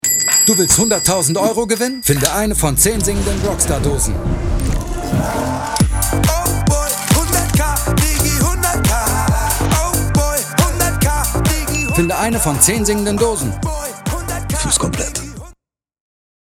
dunkel, sonor, souverän
Mittel minus (25-45)
Commercial (Werbung)